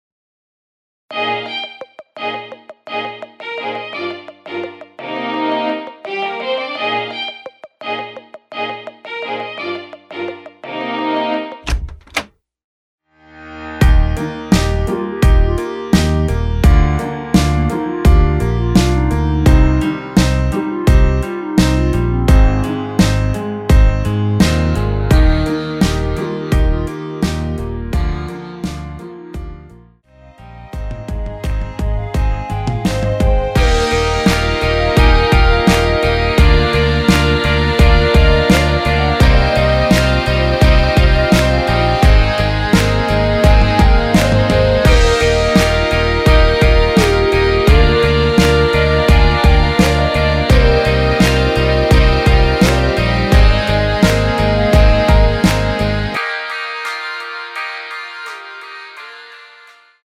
원키 멜로디 포함된 MR입니다.
Eb
멜로디 MR이라고 합니다.
앞부분30초, 뒷부분30초씩 편집해서 올려 드리고 있습니다.
중간에 음이 끈어지고 다시 나오는 이유는